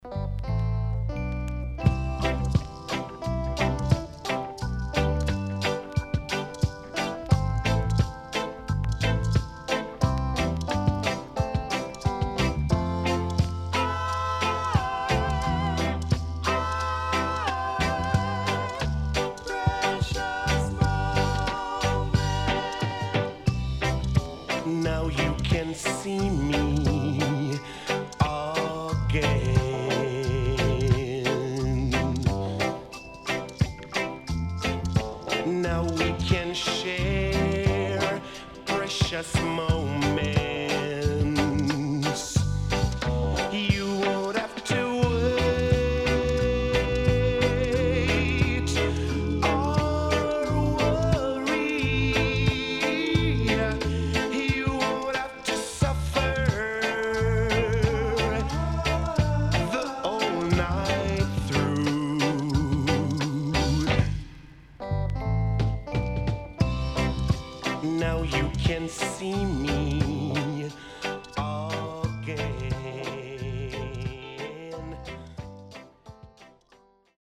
HOME > REGGAE / ROOTS  >  SWEET REGGAE  >  定番70’s
W-Side Good Vocal.定番
SIDE A:所々チリノイズがあり、少しプチノイズ入ります。